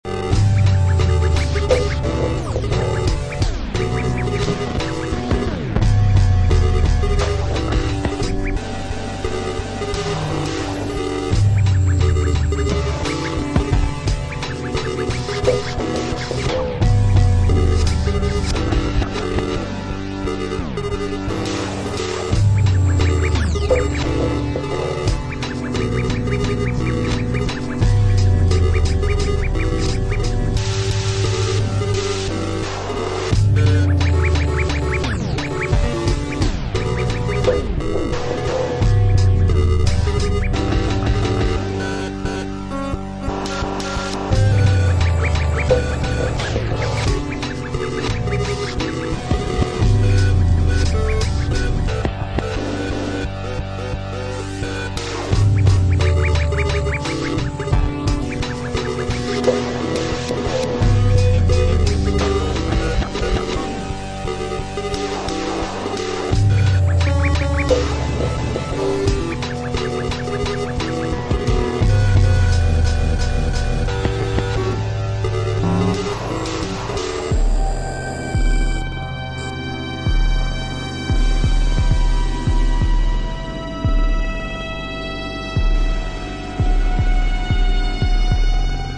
bleep and bass anthem